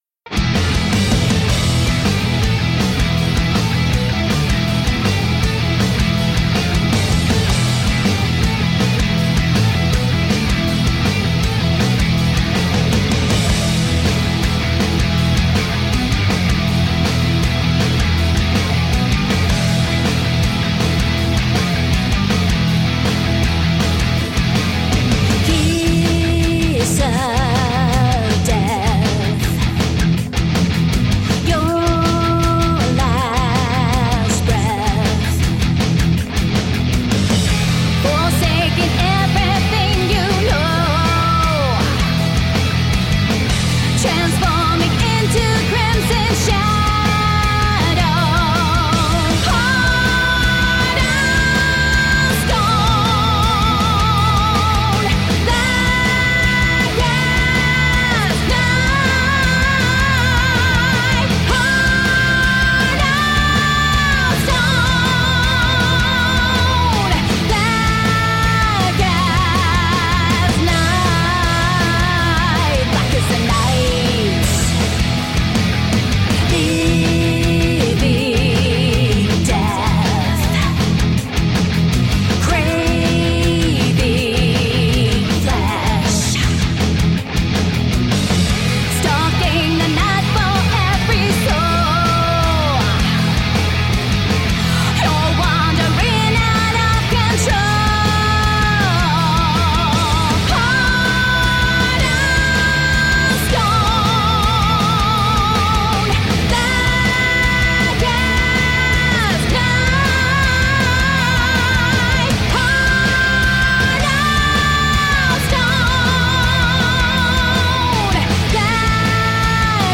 Bad ass bass with soaring femme fatale vocals.
hard rock album
with four chugging guitar riffs that seamlessly interchange